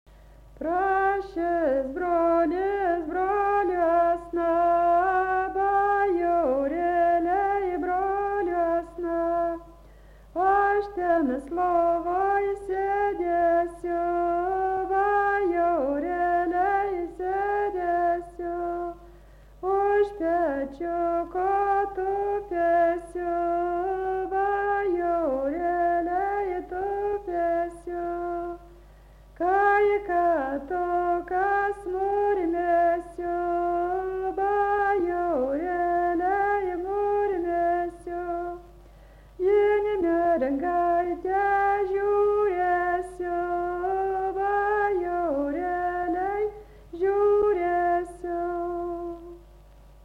Dalykas, tema daina
Erdvinė aprėptis Dargužiai
Atlikimo pubūdis vokalinis